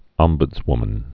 (ŏmbŭdz-wmən, -bədz-, -bdz-)